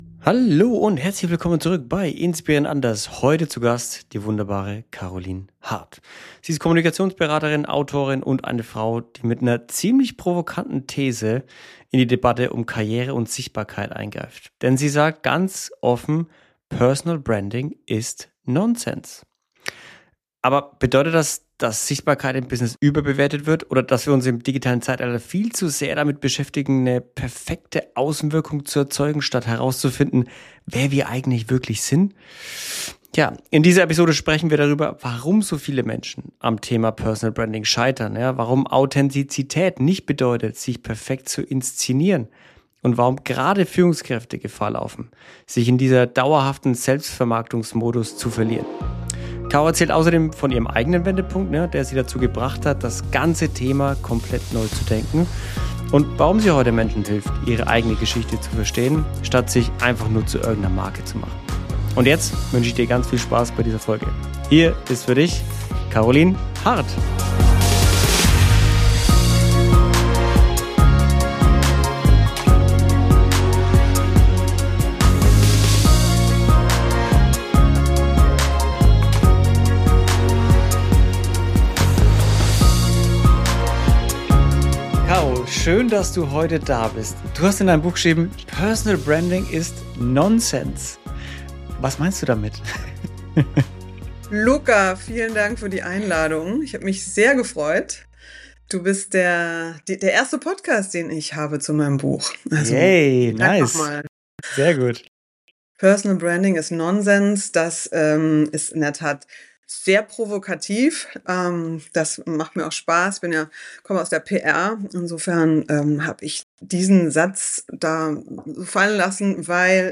In unserem Gespräch sprechen wir darüber, warum der Hype um Sichtbarkeit, Selbstvermarktung und perfekte Außendarstellung für viele eher zur Sackgasse wird – und weshalb es einen anderen, ehrlicheren Weg geben kann.